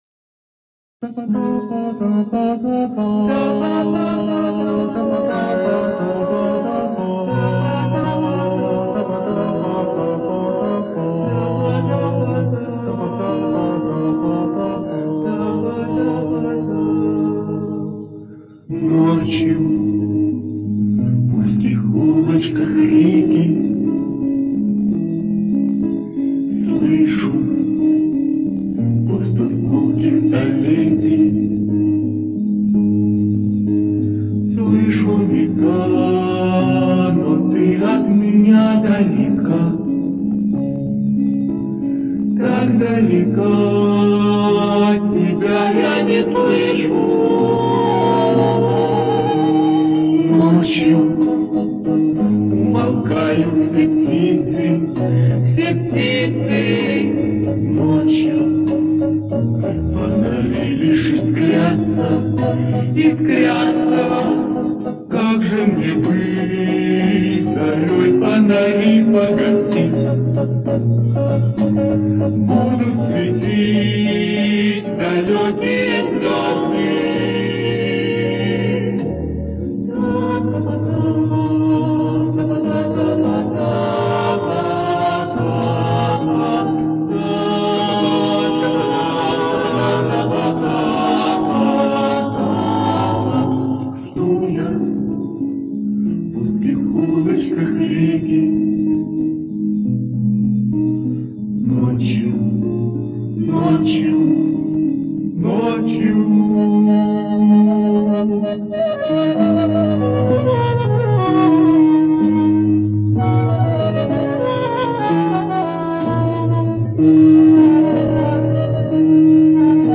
Но на моё ухо, немного медленновато по скорости.